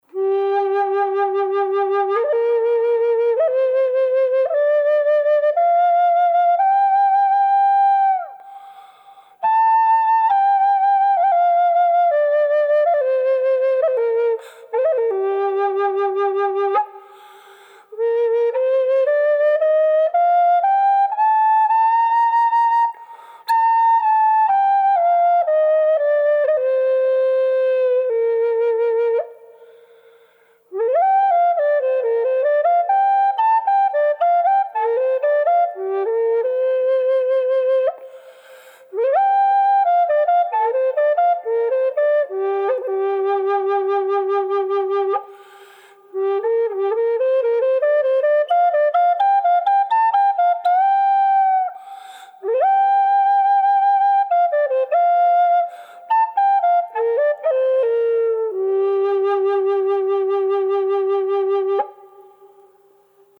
• Burly Tiger Maple Gm
The key is G-minor, which I like to think of as a "happy" key.
Reverb added to the sample of this flute for the "Canyon" sound.
Tiger_Burl_G_Wet.mp3